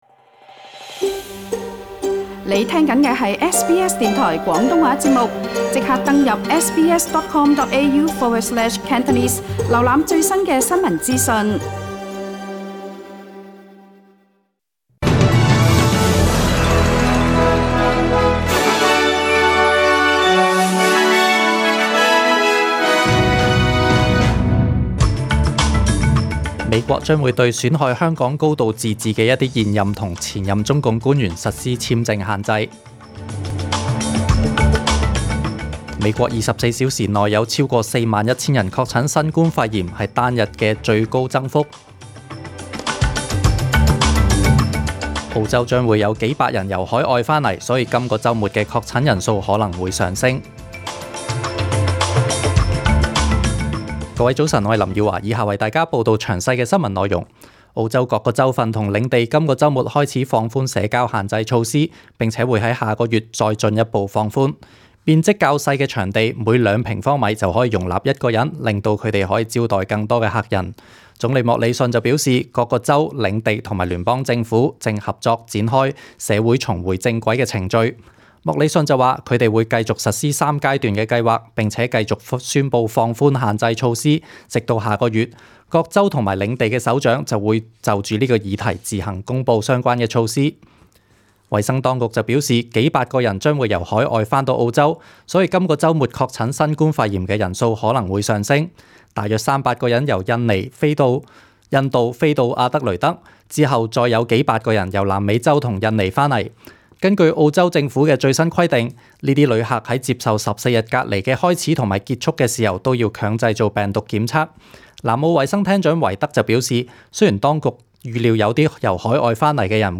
SBS中文新闻 （六月二十七日）
请收听本台为大家准备的详尽早晨新闻。